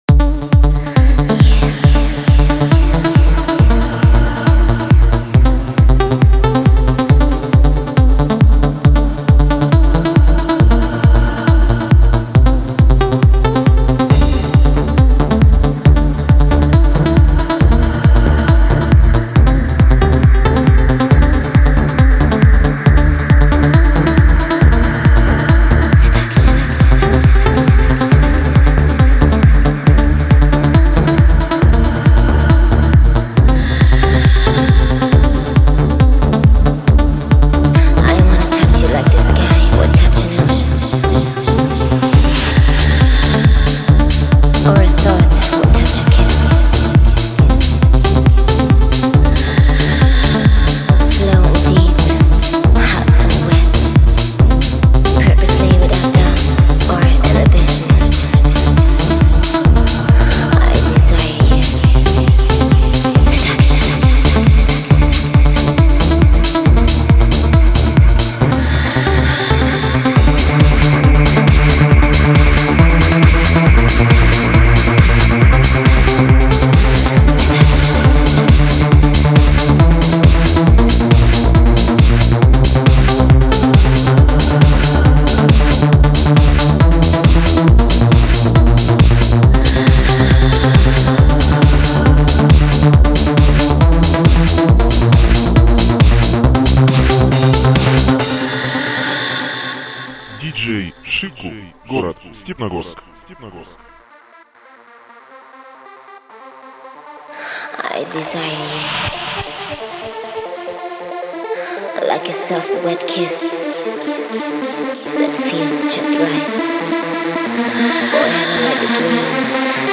Ремикс для ночного клуба